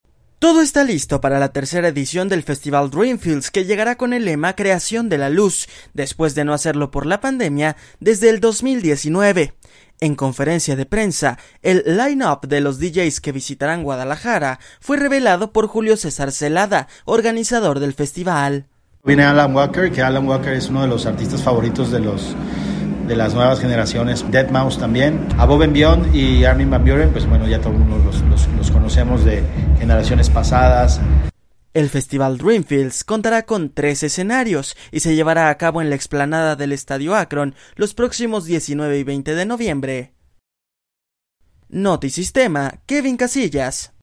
Todo está listo para la tercera edición del Festival Dreamfields que llegará con el lema “Creación de la Luz”, después de no hacerlo por la pandemia desde el 2019. En conferencia de prensa